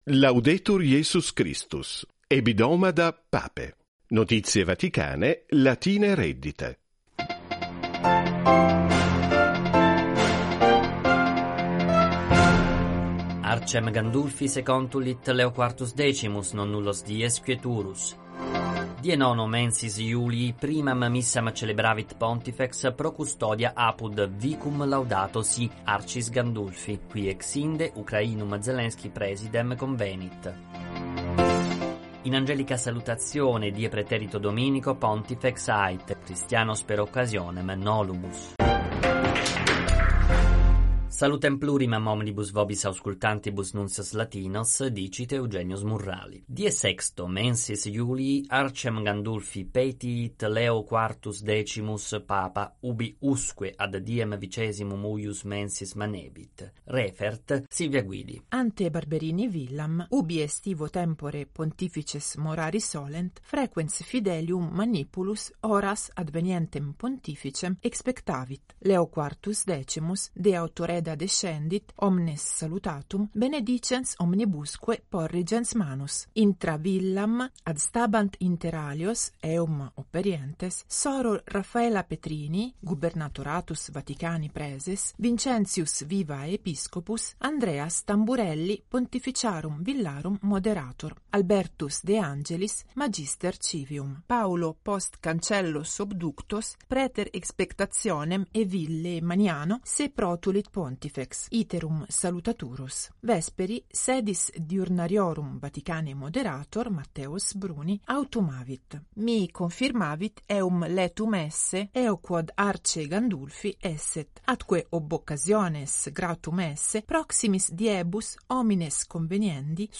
Vatican Radio News in Latin ➕ Abonnieren ➕ Folgen ✔ Abonniert ✔ Gefolgt Abspielen Abspielen Teilen Alle als (un)gespielt markieren ...